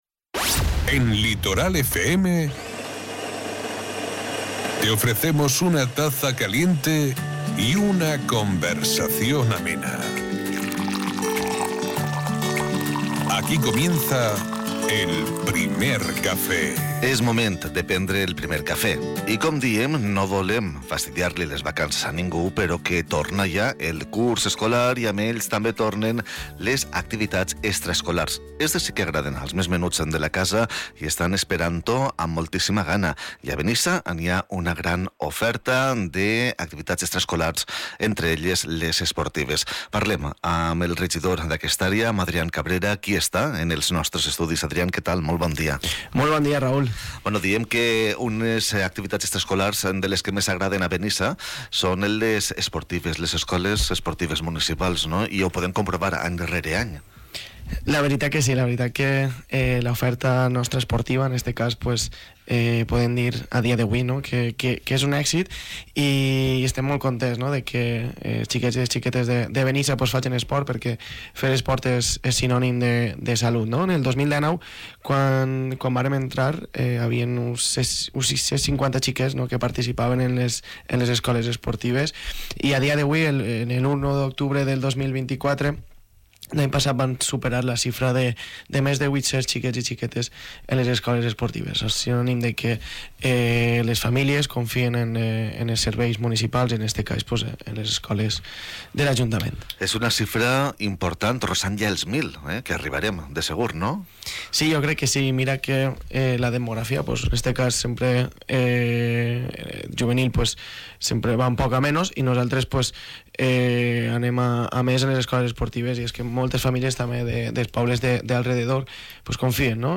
En un matí hem fet el primer cafè amb Adrián Cabrera, regidor d'Esports de l'Ajuntament de Benissa, una entrevista que ha estat el reflex d'una política activa que busca fomentar el benestar físic i emocional dels veïns a través de l'esport. Benissa compta amb una xarxa d'Escoles Esportives Municipals que inclou diverses disciplines.